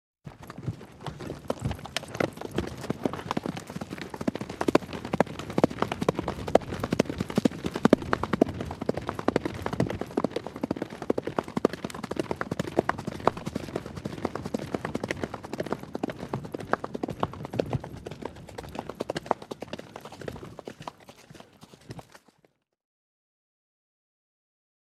دانلود صدای اسب 6 از ساعد نیوز با لینک مستقیم و کیفیت بالا
جلوه های صوتی